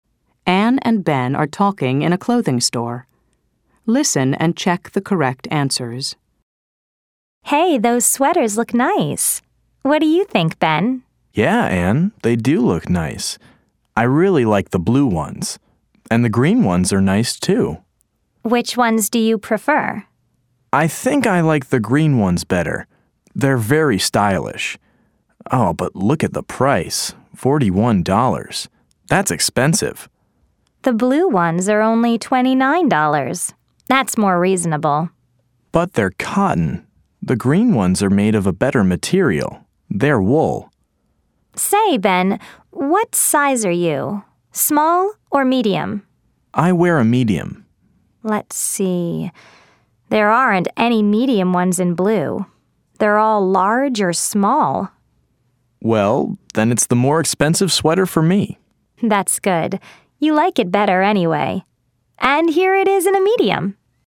Category: Listening OCEC 1